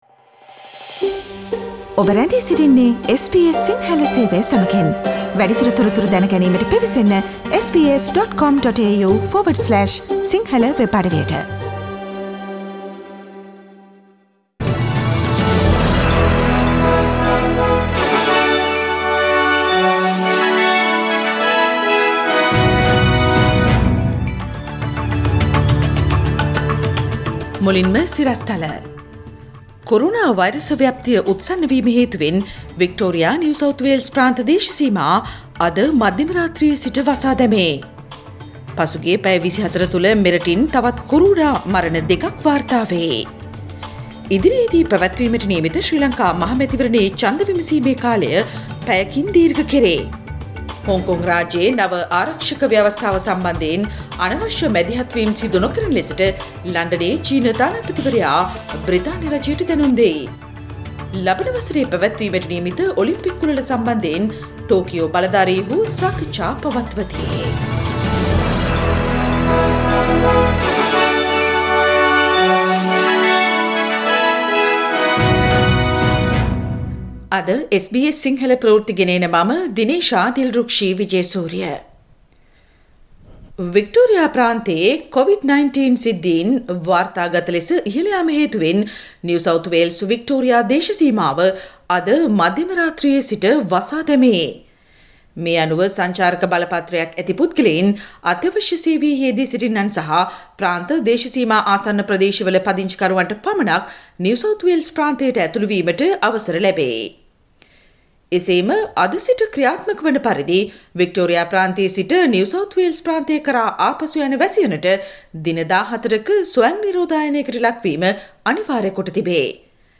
Daily News bulletin of SBS Sinhala Service: Tuesday 7 July 2020
News bulletin of SBS Sinhala Service: Tuesday 7 July 2020 Source: SBS Sinhala radio